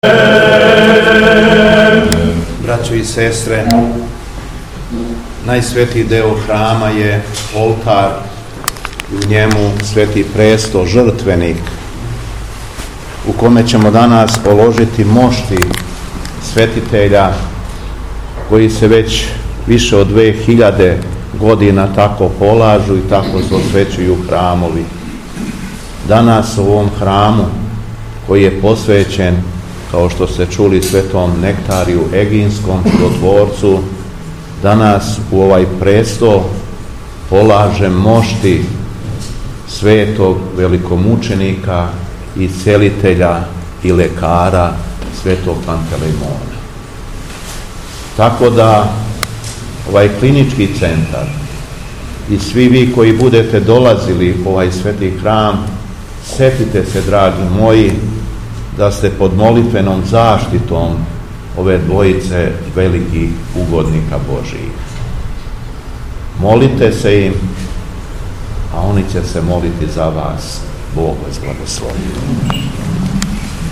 ОСВЕЋЕЊЕ ХРАМА СВЕТОГ НЕКТАРИЈА ЕГИНСКОГ ПРИ УНИВЕРЗИТЕТСКОМ КЛИНИЧКОМ ЦЕНТРУ КРАГУЈЕВАЦ
Приликом полагања светих моштију у Часну трпезу, Митрополит Јован је рекао:
Духовна поука Његовог Високопреосвештенства Митрополита шумадијског г. Јована